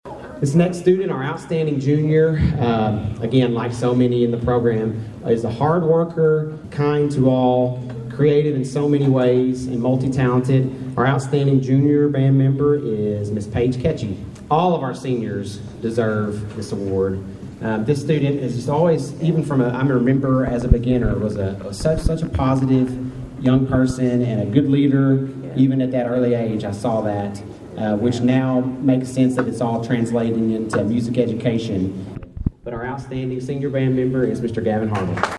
The Caldwell County High School Band of Pride held the end of the Band Banquet Friday night beginning in the high school cafeteria.